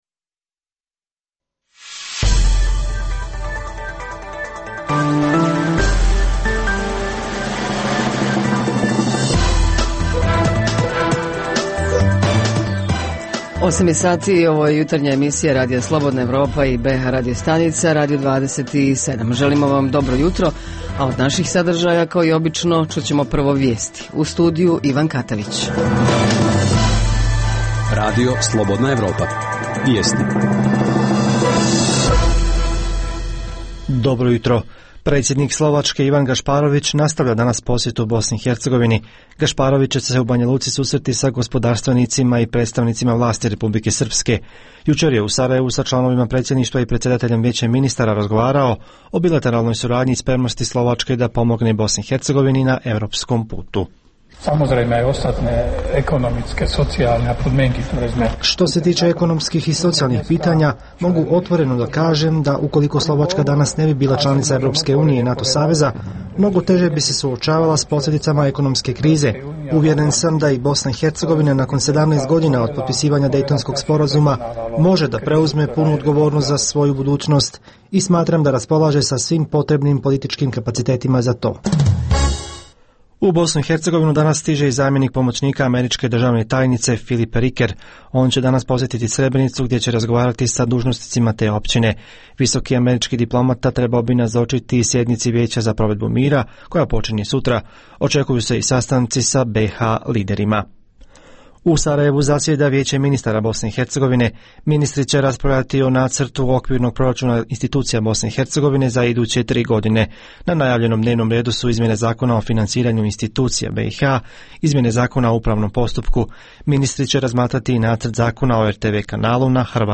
Reporteri iz cijele BiH javljaju o najaktuelnijim događajima u njihovim sredinama. Tema jutra je sigurnost u saobraćaju Redovna rubrika Radija 27 srijedom je “Vaša prava".